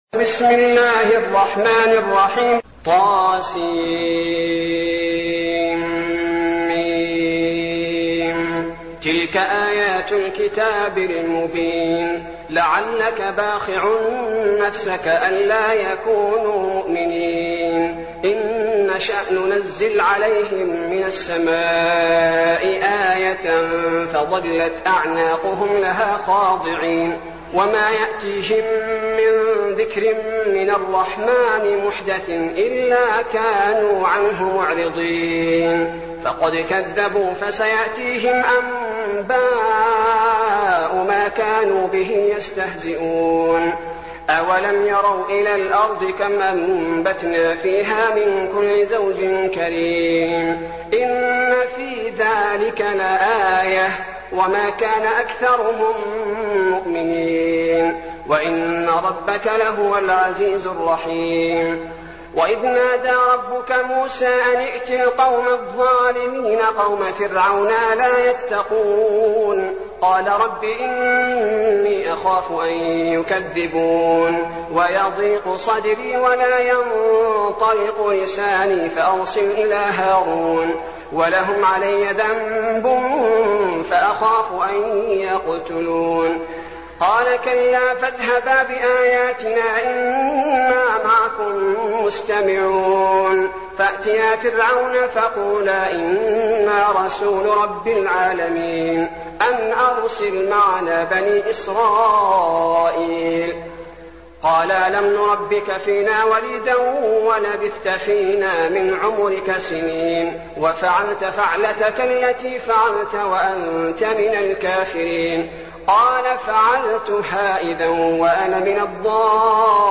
المكان: المسجد النبوي الشيخ: فضيلة الشيخ عبدالباري الثبيتي فضيلة الشيخ عبدالباري الثبيتي الشعراء